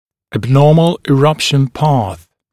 [æb’nɔːml ɪ’rʌpʃn pɑːθ][эб’но:мл и’рапшн па:с]неправильная траектория прорезывания